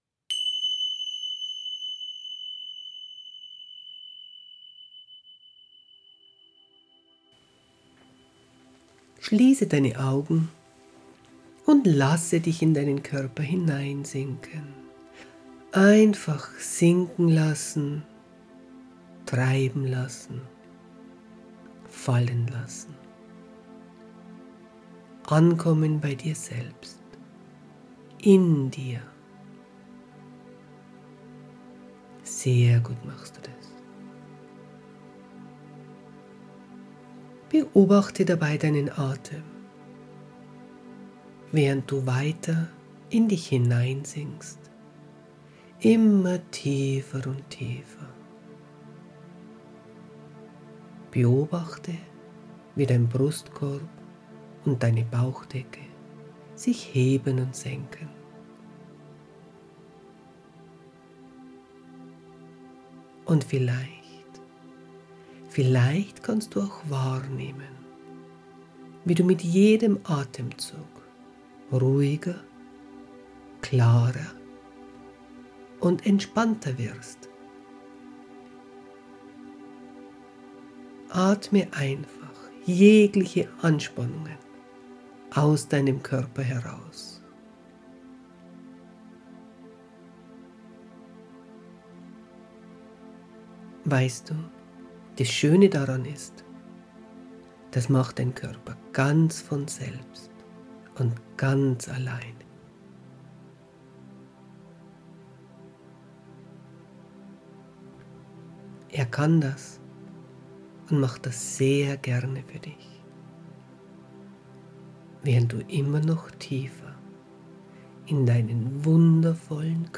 meditation-vollmond-ver-nderung.mp3